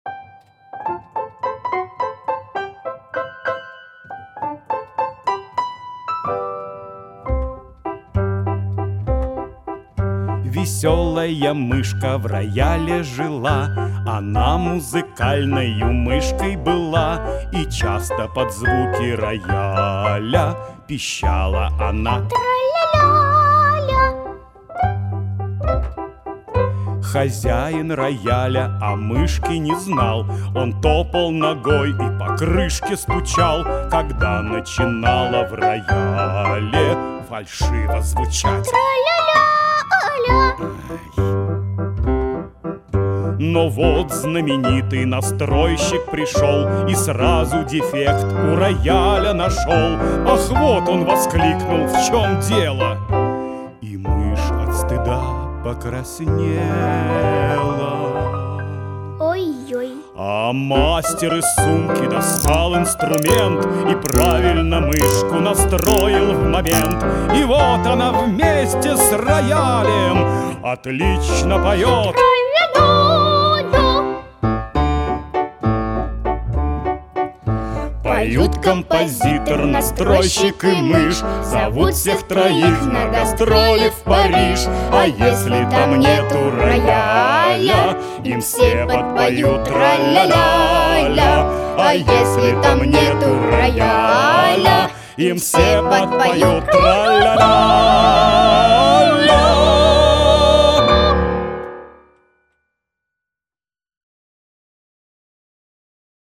Аудиокнига Детская площадка №2 | Библиотека аудиокниг
Aудиокнига Детская площадка №2 Автор Андрей Усачев Читает аудиокнигу Андрей Усачев.